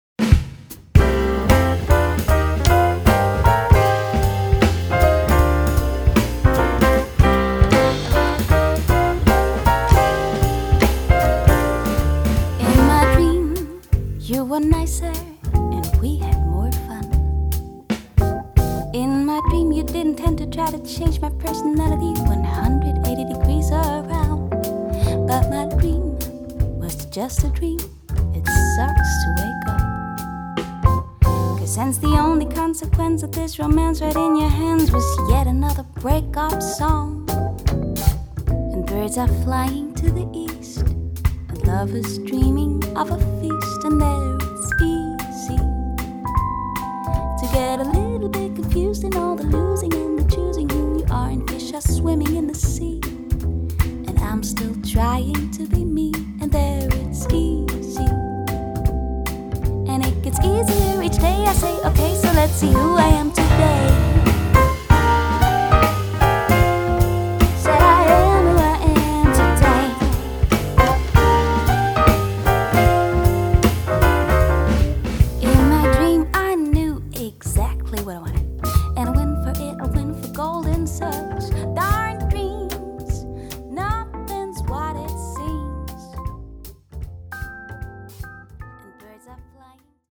Studioalbum
Rock‘n‘Roll